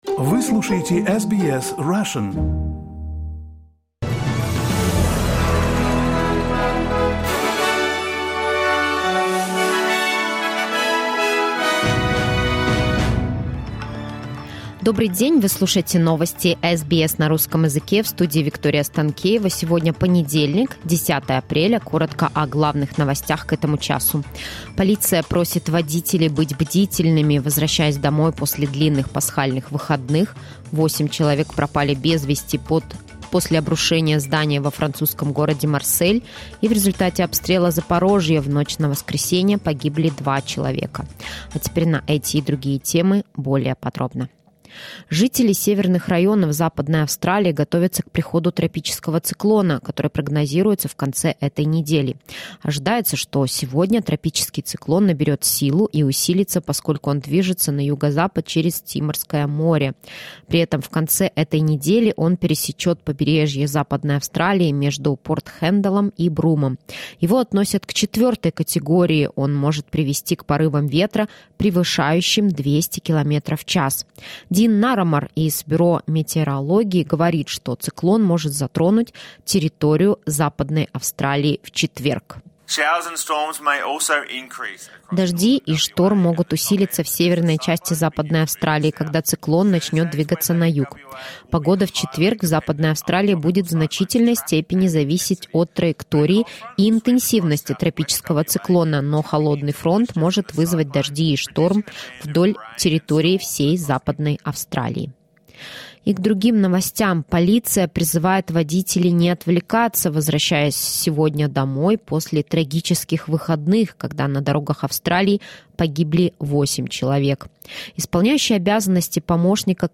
SBS news in Russian — 10.04.2023